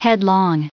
added pronounciation and merriam webster audio
382_headlong.ogg